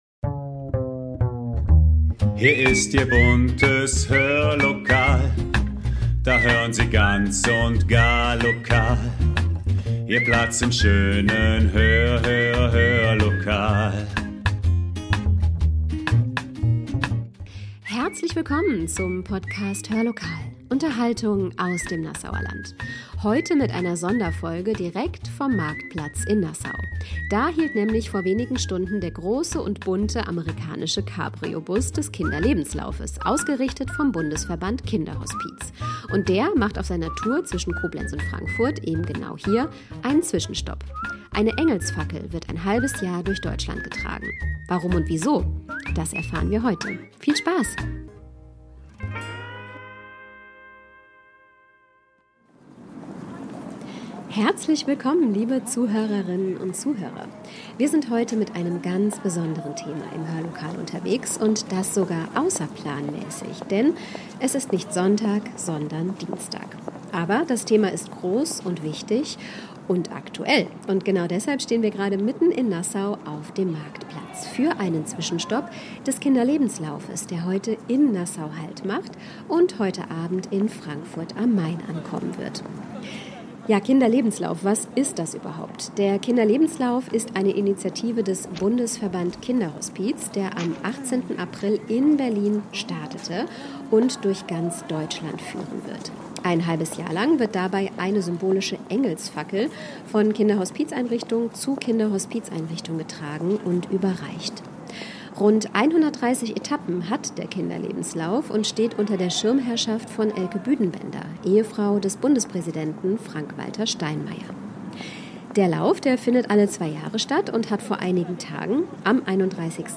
Beschreibung vor 1 Jahr Heute überraschen wir mit einer Sonderfolge direkt vom Marktplatz in Nassau.